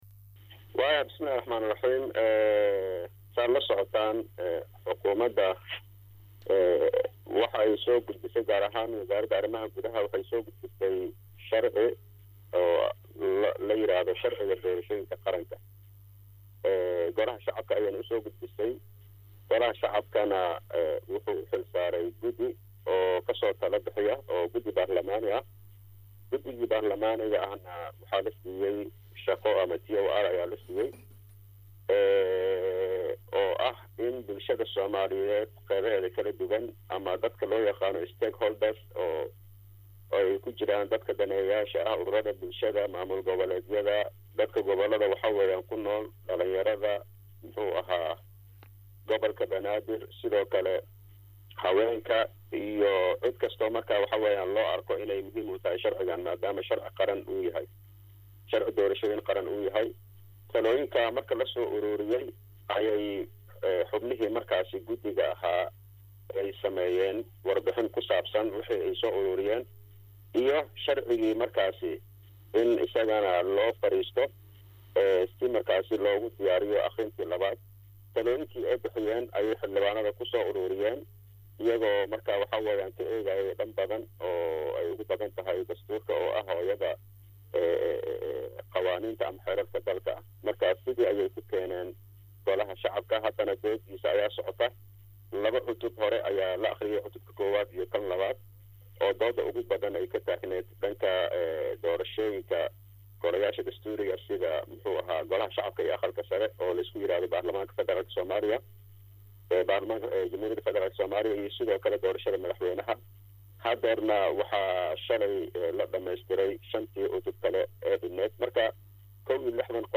Hadaba Xildhibaan C/fitaax Qaasim oo ka mida xubnaha guddiga diyaarinta sharciga doorashooyinka qaranka ayaa si guud u faah faahinayo, macluumaad badan oo ku saabsan xaalada doorashooyinka dalka iyo si gaar ah sharcigaan iyo waxyaabaha uu ku saabsanyahay. Related posts Wasiirka Maaliyadda XFS oo khudbad ka jeediyay kulanka Bangiga Dunida ee Washington April 17, 2026 Mas’uuliyiinta Buuhoodle oo u istaagay ciribtirka muqaadaraadka April 17, 2026 Hoos ka dhageyso wareysigga Xildhibaan Cabdul fitaax Qaasim oo dhameystiran.